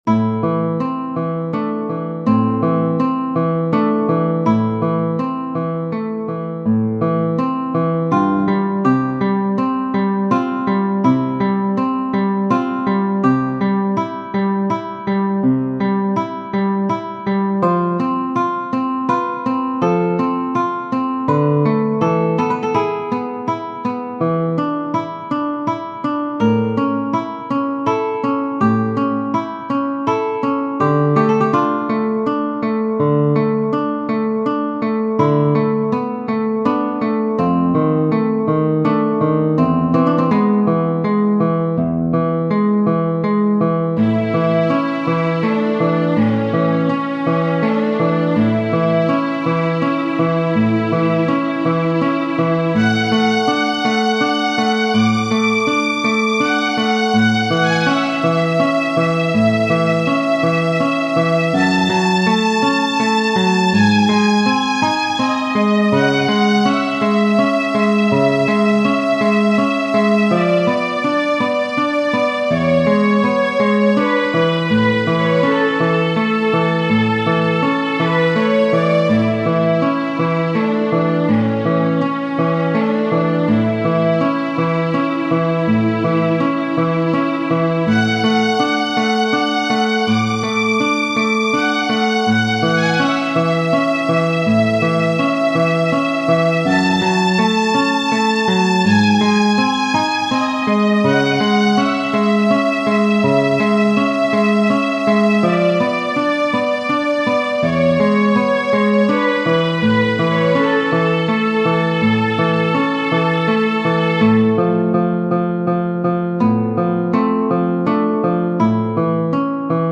Tarrega, F. Genere: Romantiche Ho provato a rendere più luminosa la seconda parte di "Recuerdos de la Alhambra" con l'aggiunta del violino.